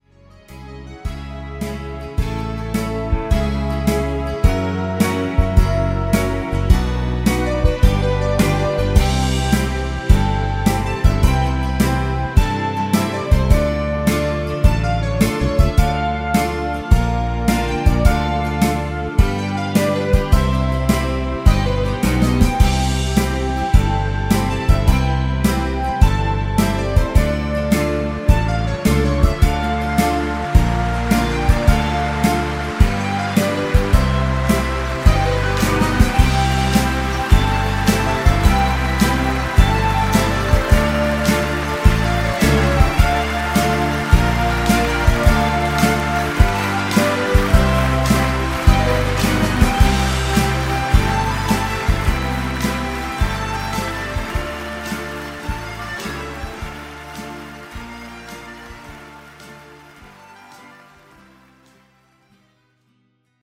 avec applaudissement à la fin